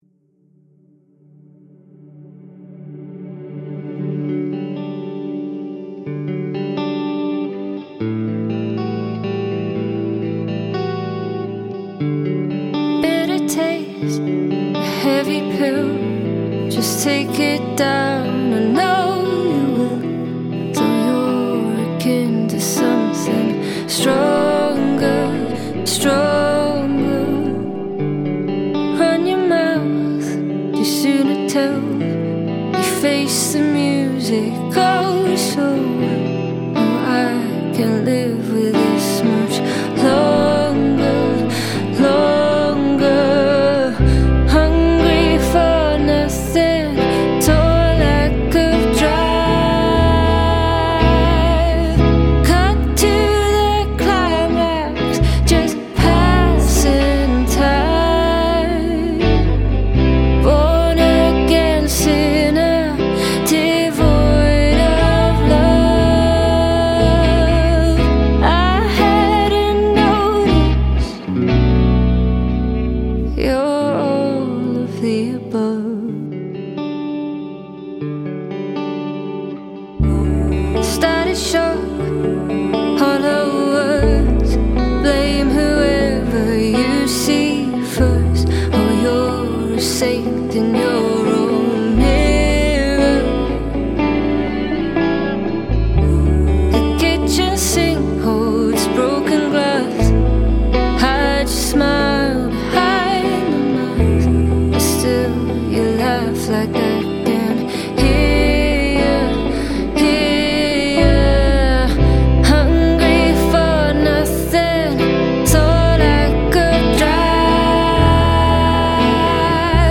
So full with so few elements.
Those wide panned, airy BG vox.
The ending solo is full of haunting and longing.
Those backing vocals are just heavenly!